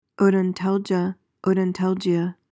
PRONUNCIATION:
(oh-don-TAL-juh, -jee-uh)